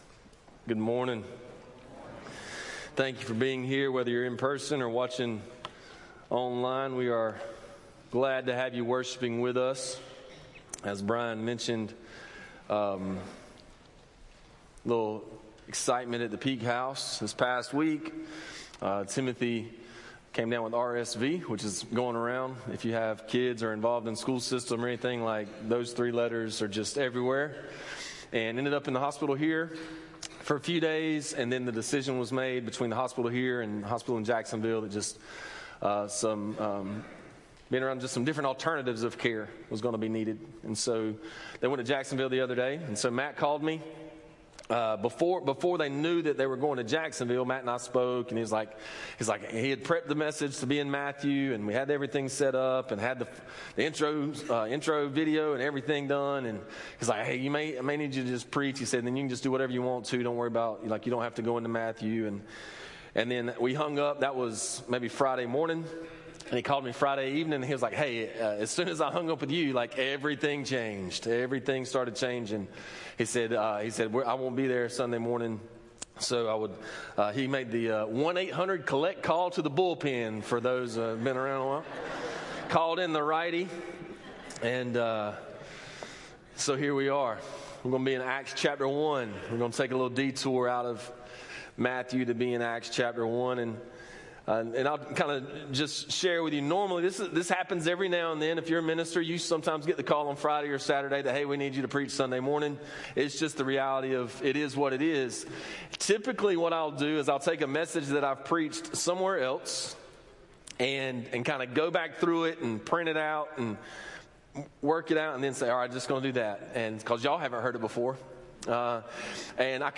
A message from the series "First Things First."